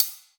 • Dry Pedal Hi-Hat Sample C# Key 131.wav
Royality free hat one shot tuned to the C# note. Loudest frequency: 10202Hz
dry-pedal-hi-hat-sample-c-sharp-key-131-NyI.wav